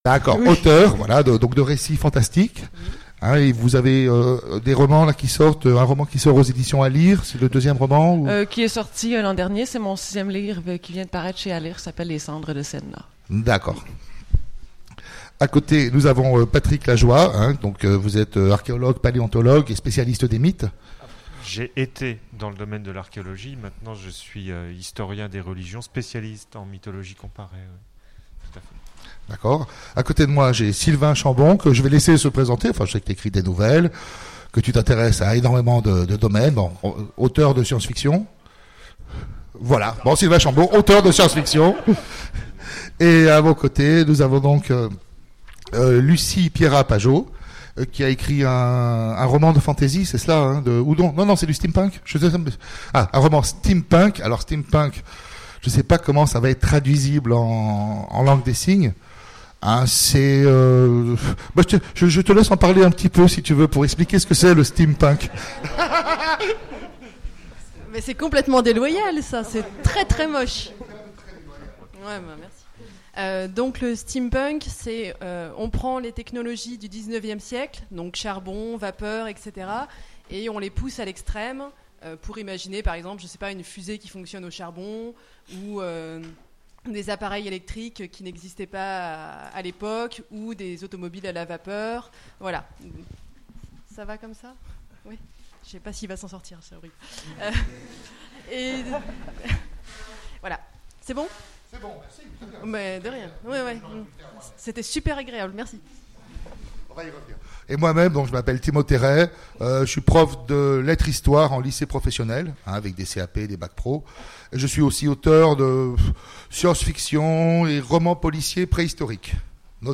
Utopiales 2017 : Conférence Le temps du langage en SF
Il manque les premières minutes d'introduction, nos excuses.